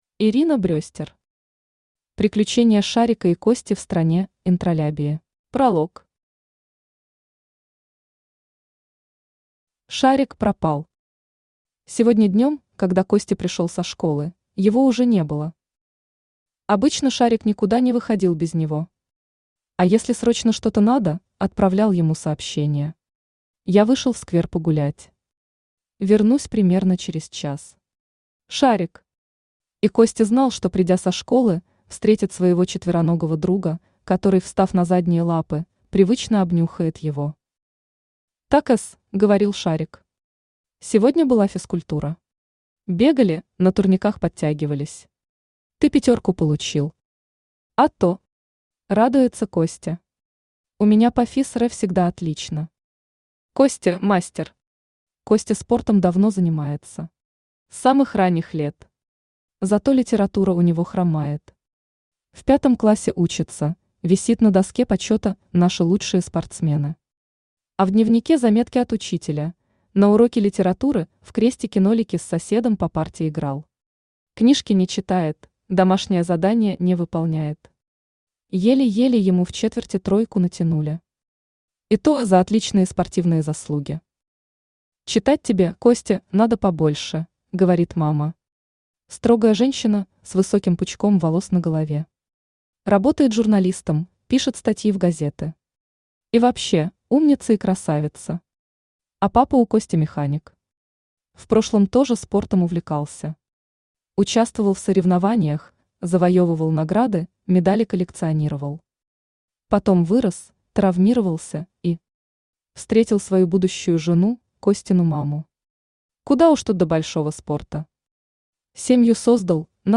Аудиокнига Приключения Шарика и Кости в Стране Интралябии | Библиотека аудиокниг
Aудиокнига Приключения Шарика и Кости в Стране Интралябии Автор Ирина Брестер Читает аудиокнигу Авточтец ЛитРес.